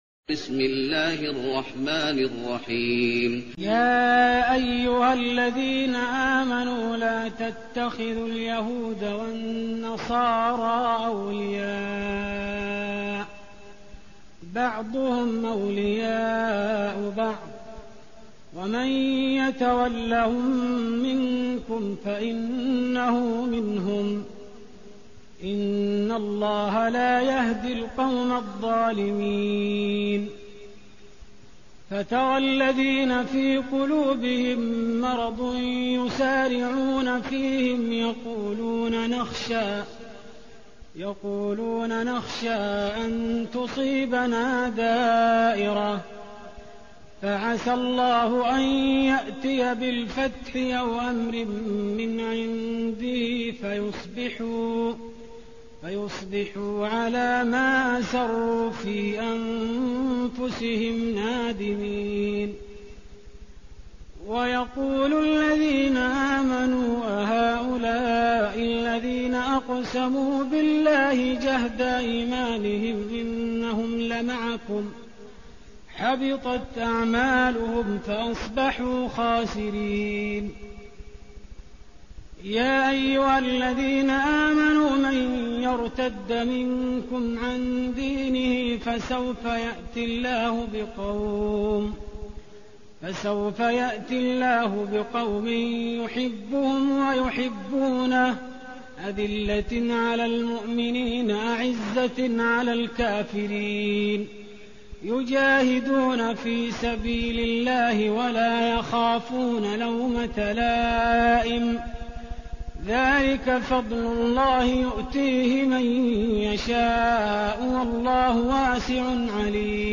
تهجد رمضان 1416هـ من سورة المائدة (51-108) Tahajjud Ramadan 1416H from Surah AlMa'idah > تراويح الحرم النبوي عام 1416 🕌 > التراويح - تلاوات الحرمين